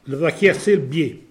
Localisation Petosse
Catégorie Locution